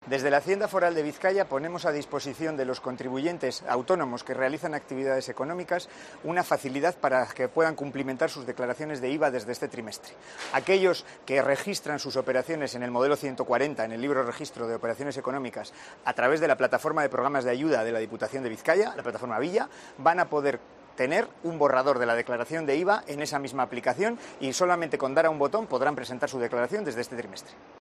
Iñaki Alonso, director de hacienda de Bizkaia